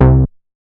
MoogAgress B.WAV